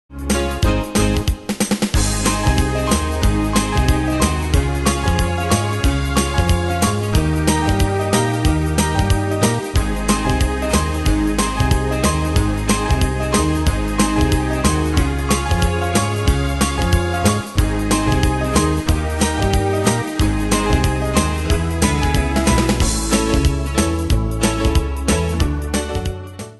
Style: Oldies Ane/Year: 1961 Tempo: 184 Durée/Time: 2.32
Danse/Dance: Rock'N'Roll Cat Id.
Pro Backing Tracks